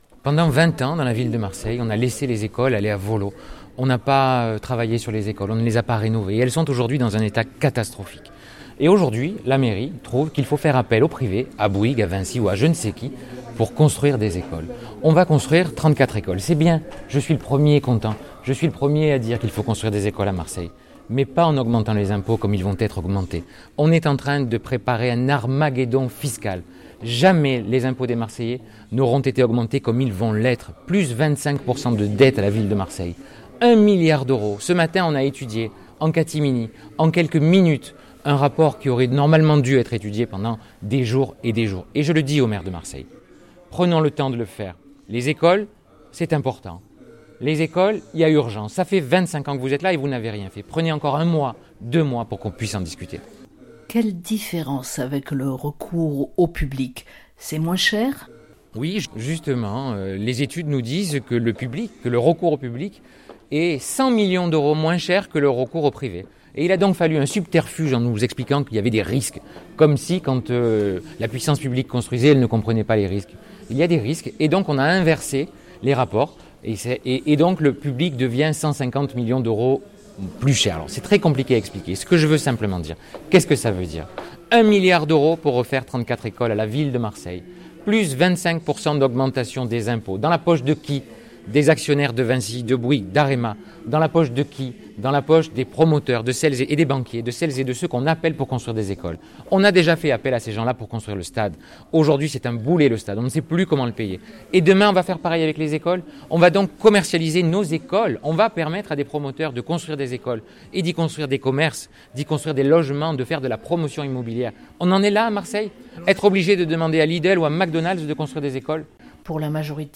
Les entretiens